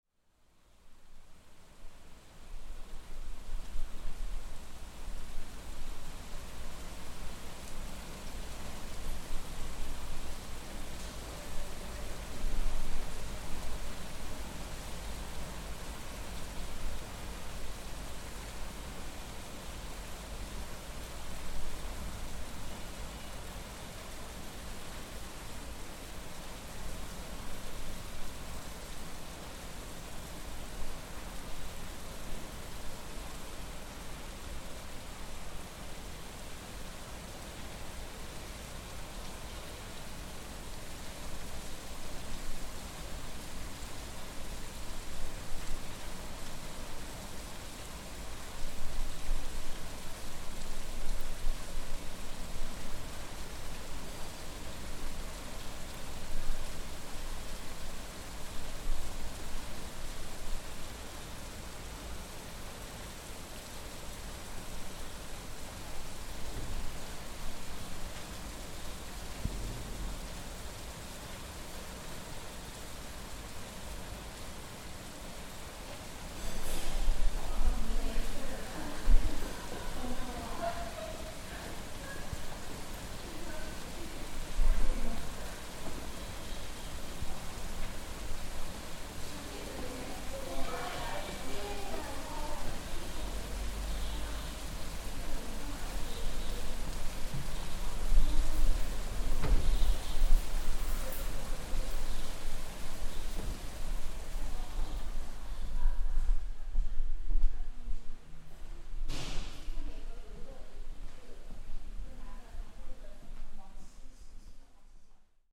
Morning rain with voices
Here is a recent recording from a Teachers Training College in Qixia, Nanjing.
rain-with-voices.mp3